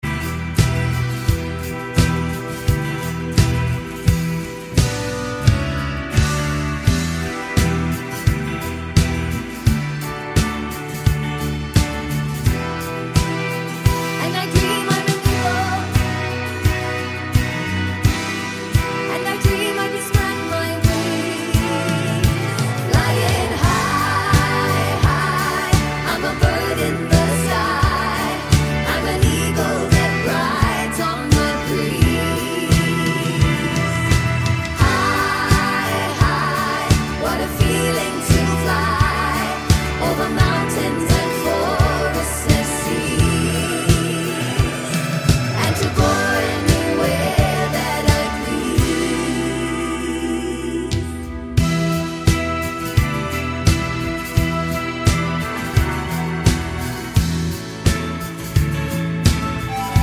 for solo female Pop (1970s) 3:42 Buy £1.50